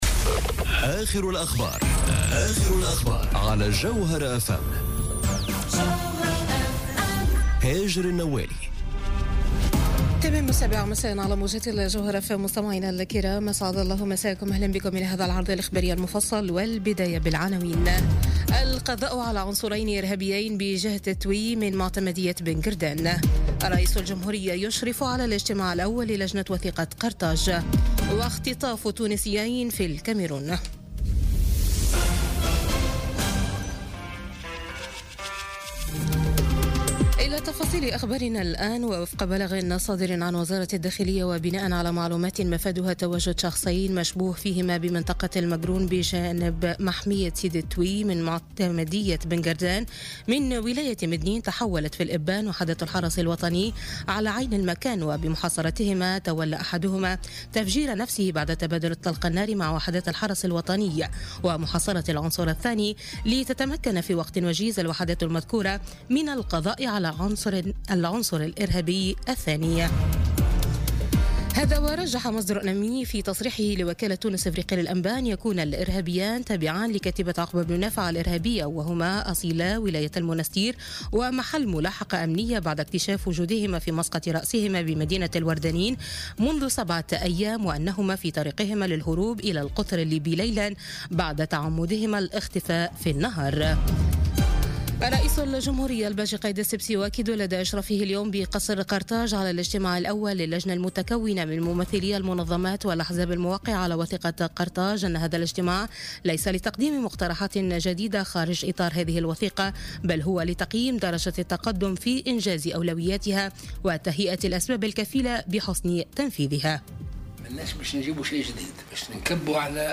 Journal Info 19h00 du lundi 19 Mars 2018